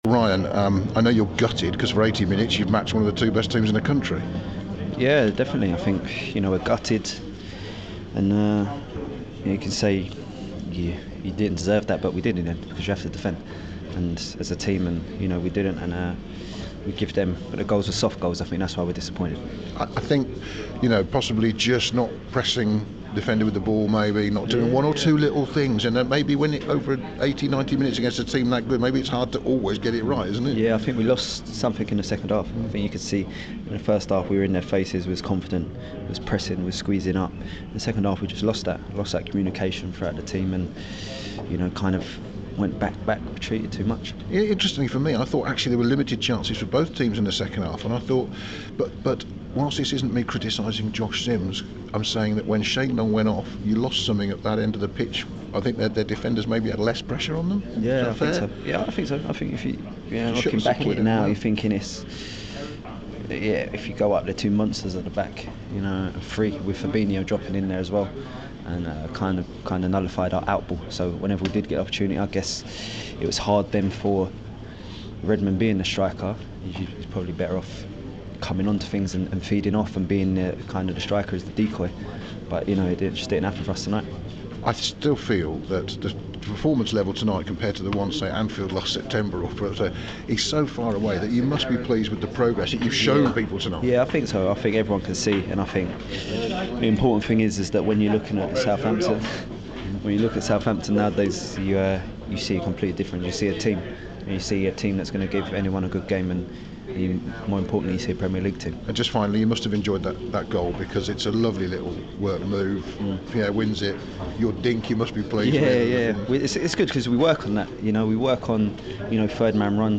Ryan Bertrand speaking after the 3-1 defeat to Liverpool